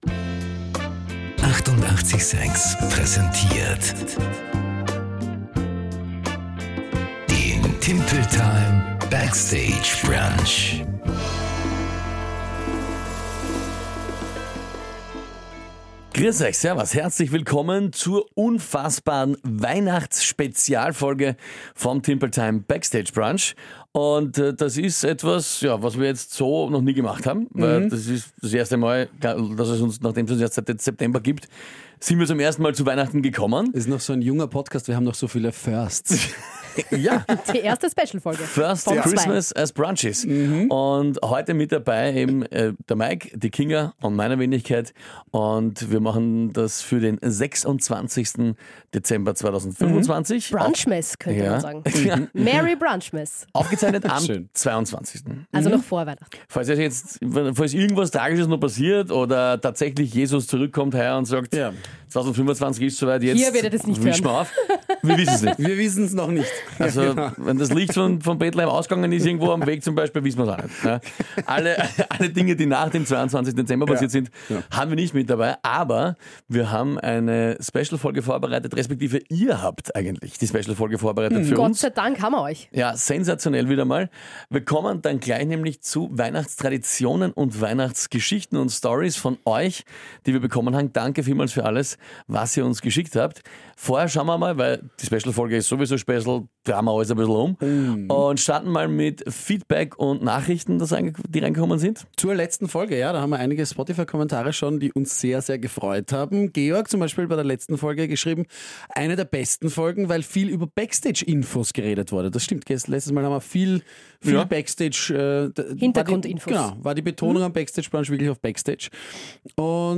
Wir immer, geht aber nichts ohne eure Hilfe, also DANKE für die unzähligen Sprachnachrichten und Geschichten, die wir von euch bekommen haben zum Thema Weihnachtstraditionen und Weihnachts-Stories.